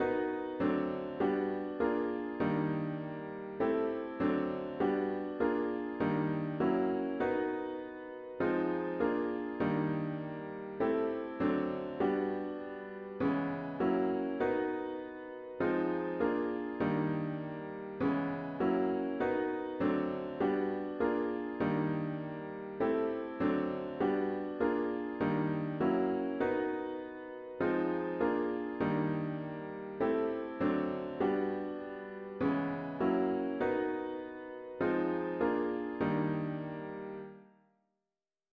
A chords only version of the score is found here.